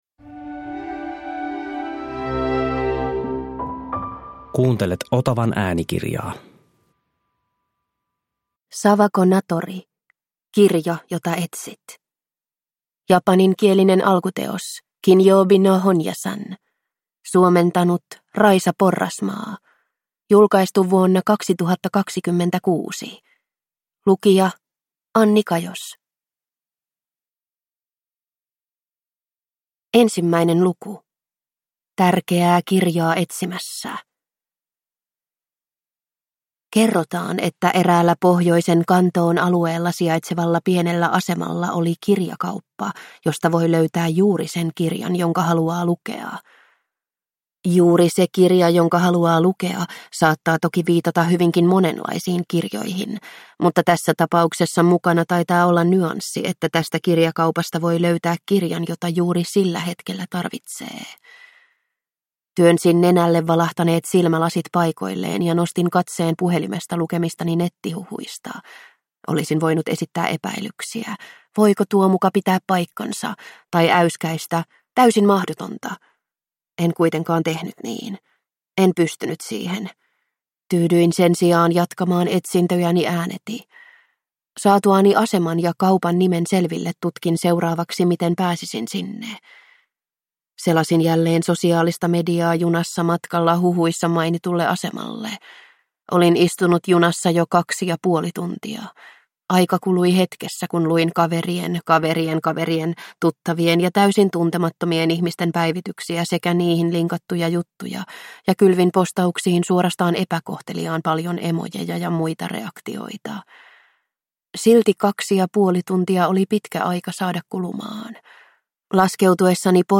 Kirja, jota etsit – Ljudbok